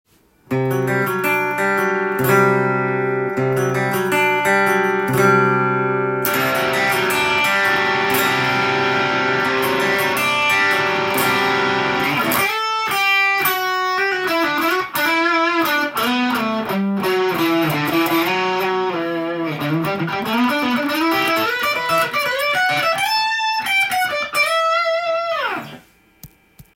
【Vin-Antique/9OVERDRIVE9/Ibanez/KORG】歪み系エフェクター
低音も出ますが、高音の鈴のような音がでる感じが好印象。